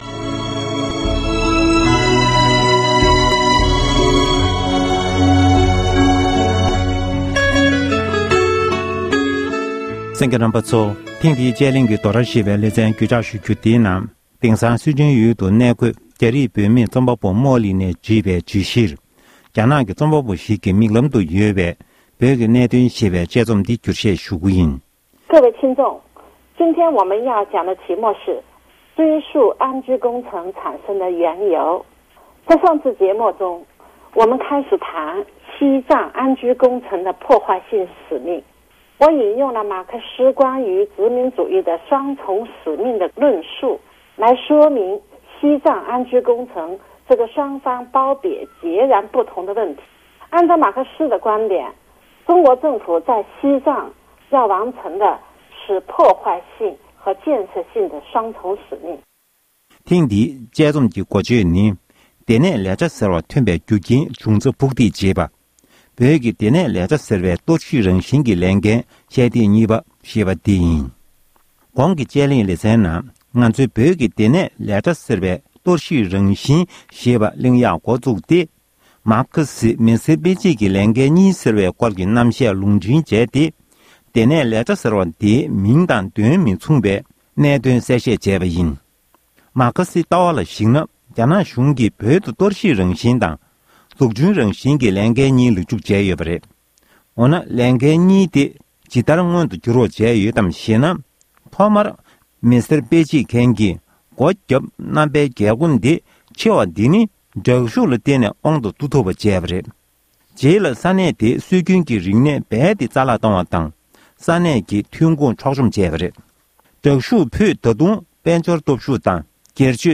བོད་སྐད་ཐོག་ཕབ་བསྒྱུར་གྱིས་སྙན་སྒྲོན་ཞུས་པར་གསན་རོགས༎